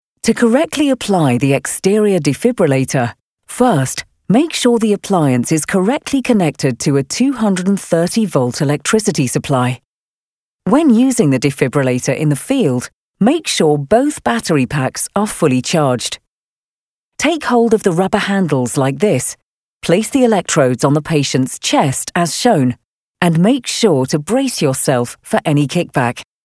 English (British)
Medical Narration